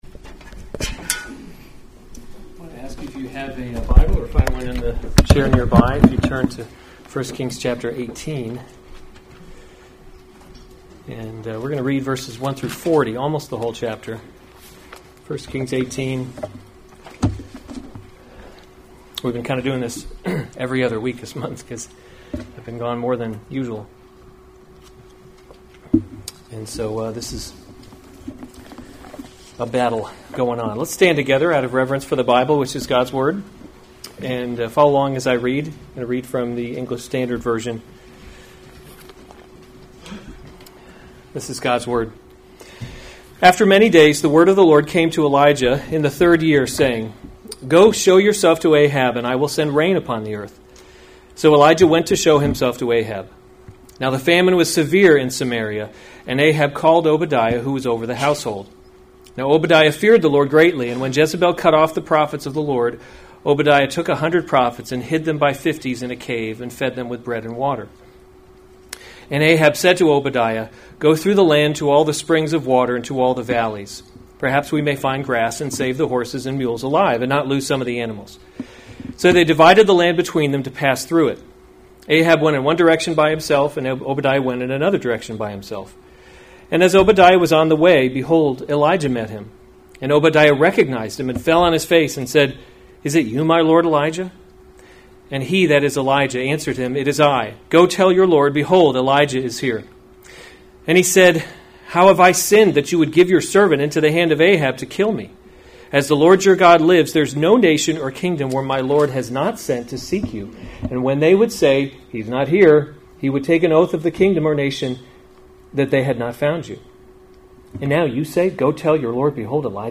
June 1, 2019 1 Kings – Leadership in a Broken World series Weekly Sunday Service Save/Download this sermon 1 Kings 18:1-40 Other sermons from 1 Kings Elijah Confronts Ahab 18:1 After […]